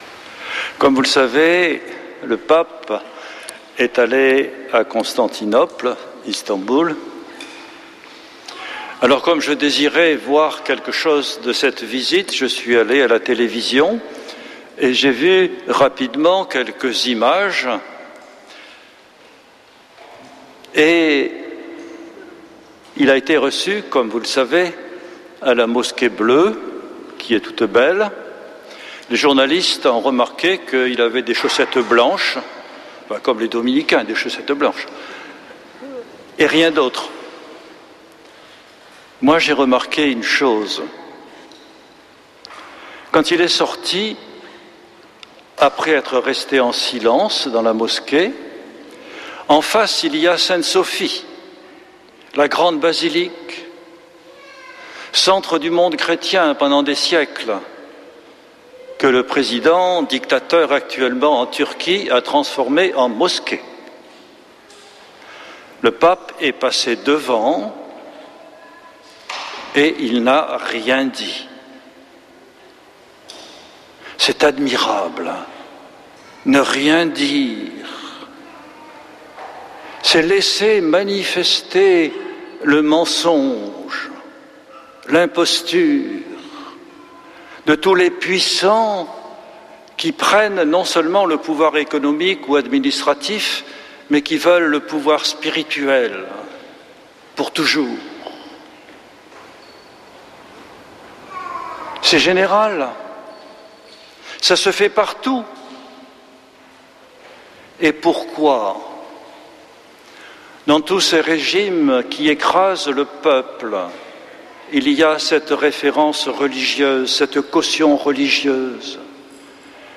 dimanche 7 décembre 2025 Messe depuis le couvent des Dominicains de Toulouse Durée 01 h 28 min